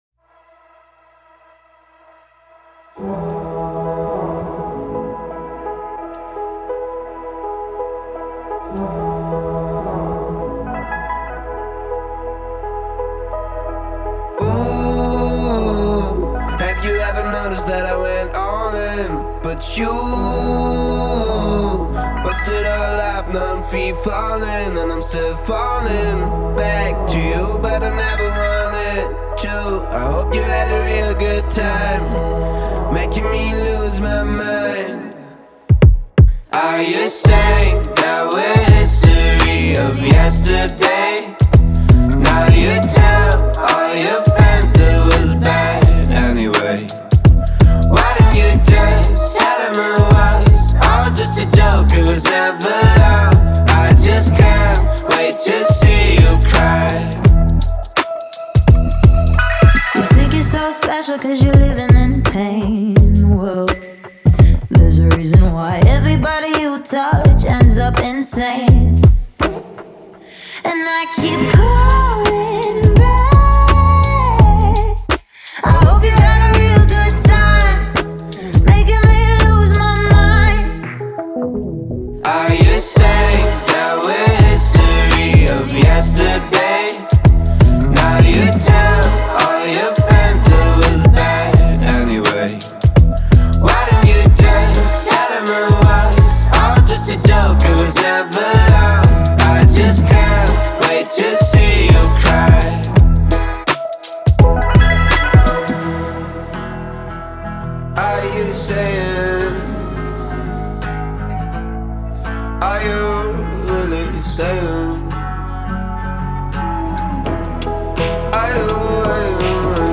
• Жанр: Pop